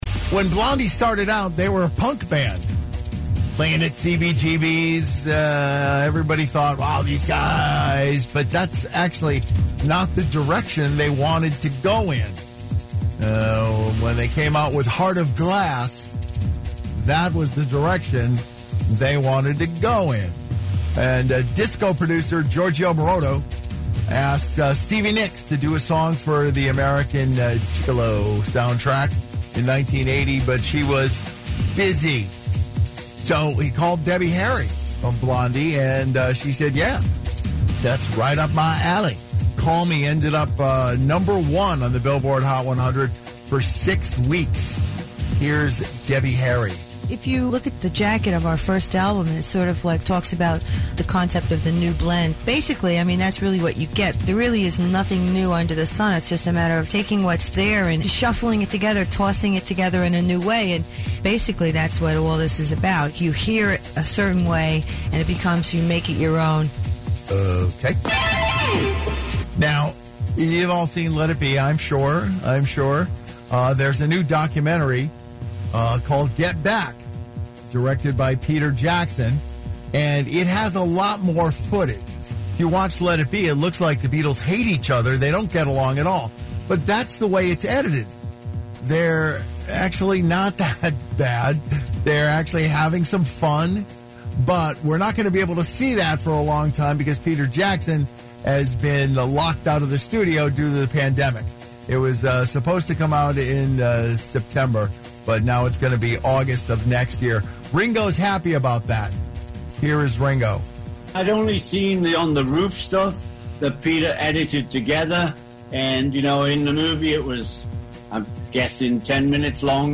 Here is Ringo on the documentary.